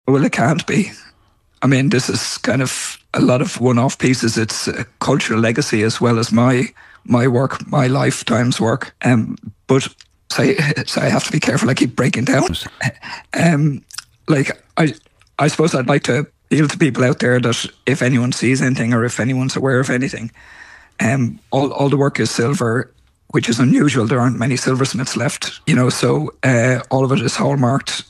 Speaking on Morning Ireland, he said his work can't be replaced.